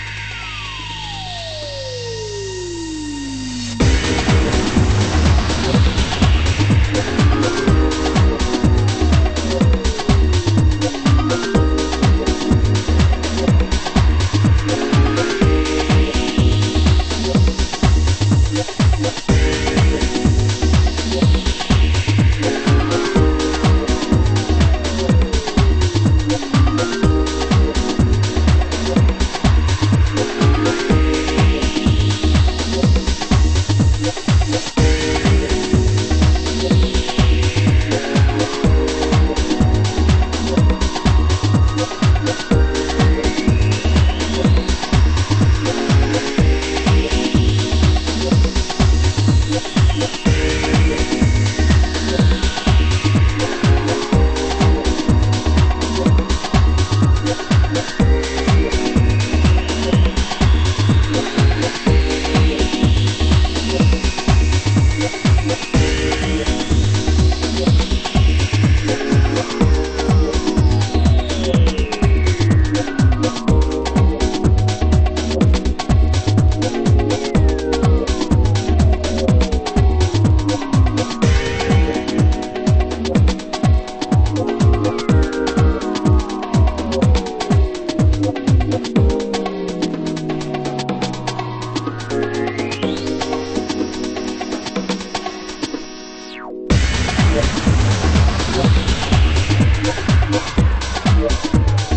盤質：盤面良好ですが少しチリノイズ有